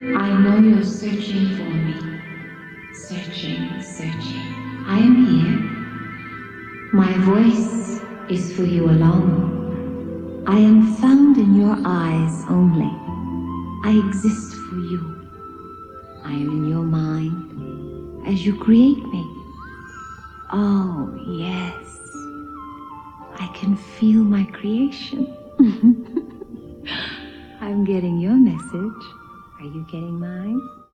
Mermeia giggles to Attichitcuk